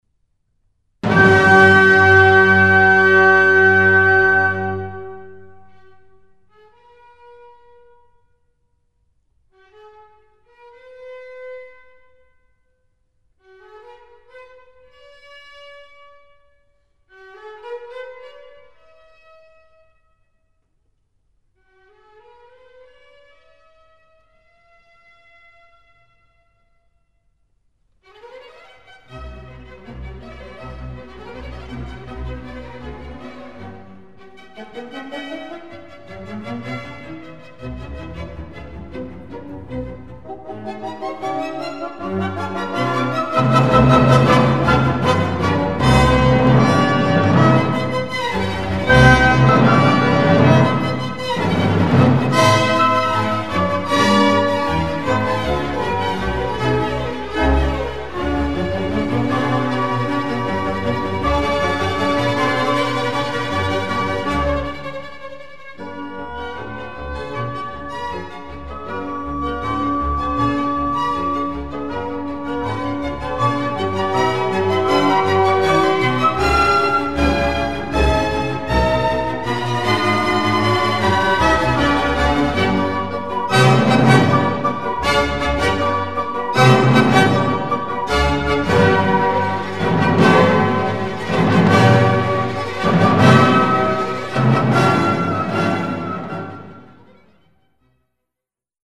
4. Satz: Wieder in Sonatenhauptsatzform
Thema: Der Beginn ist wie ein mehrmaliger Anlauf. Hier folgen Einleitung, 1. und 2. Thema in einem Ausschnitt.